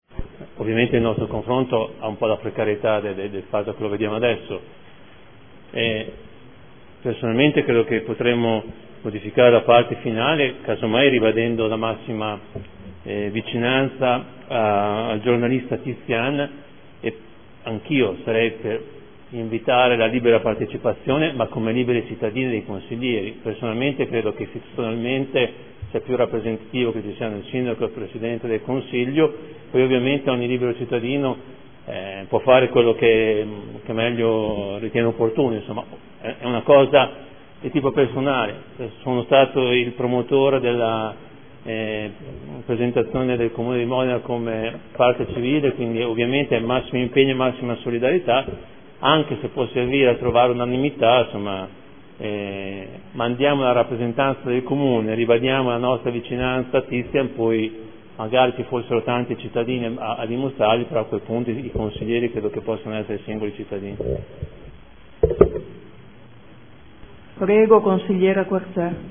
Seduta del 13/11/2014 Ordine del giorno n. 145330. Rafforzamento delle politiche contro le mafie.